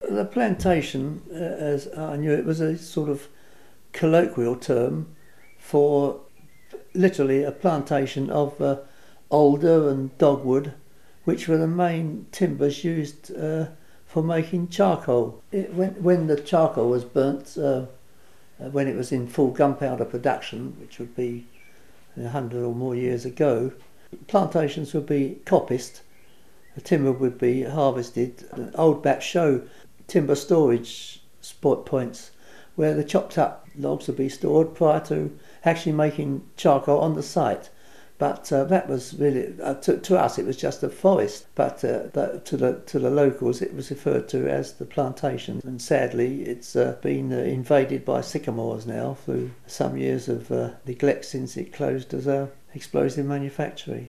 WAOH - Waltham Abbey Oral History